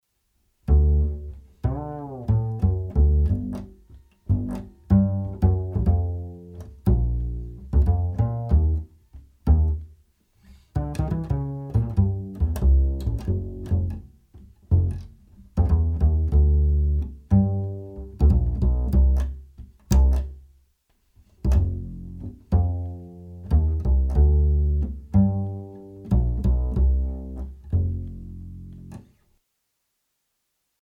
Отписался же, во на чудо плагине знатный аналог и сёр за сек нарулил Вложения bass.mp3 bass.mp3 1,2 MB · Просмотры: 298